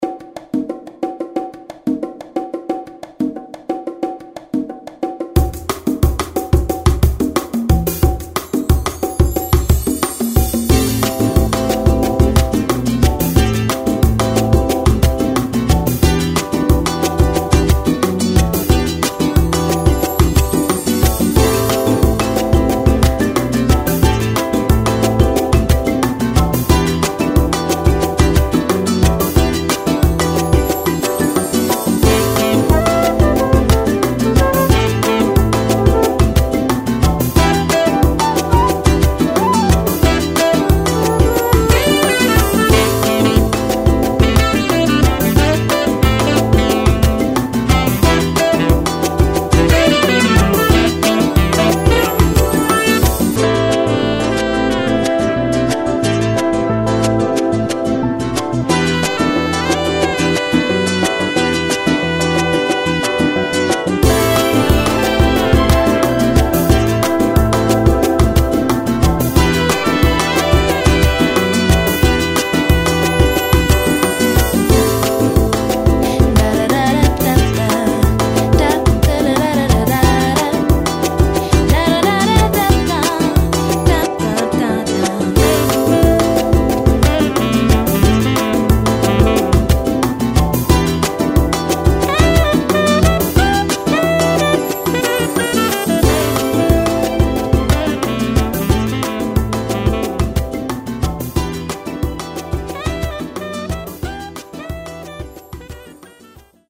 ・Brazil'66：90BPM